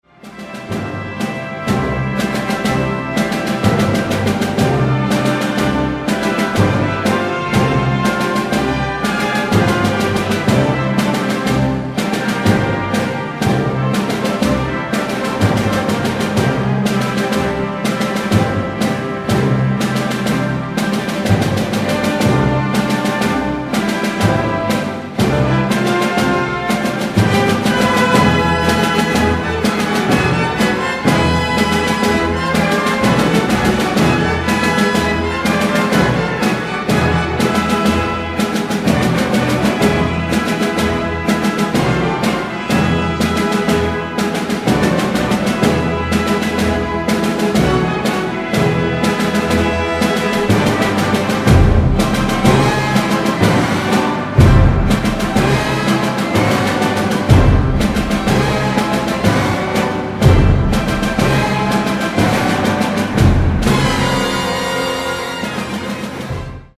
El Bolero debe su popularidad mundial a su melodía envolvente.
“Es una danza en un movimiento muy moderado y constantemente uniforme, tanto por la melodía como por la armonía y el ritmo, este último marcado sin cesar por el tambor. El único elemento de diversidad es aportado por el crescendo orquestal”: Ravel